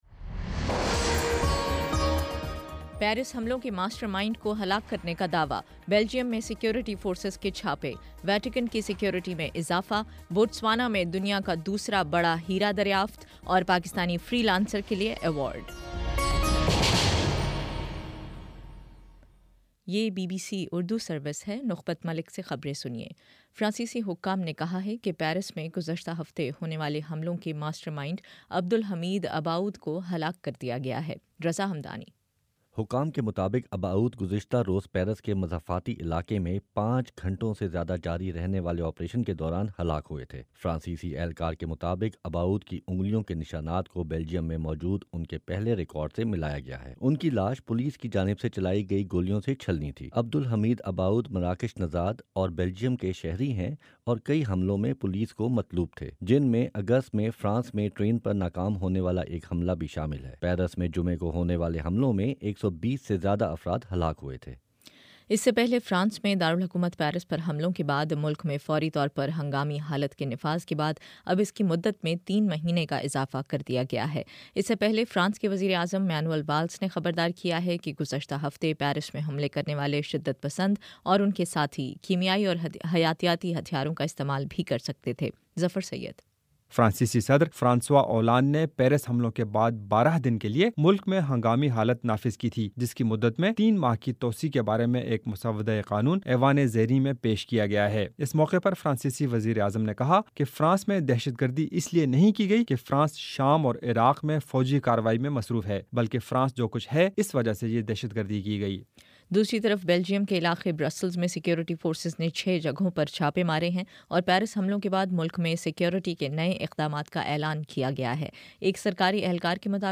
نومبر 19: شام سات بجے کا نیوز بُلیٹن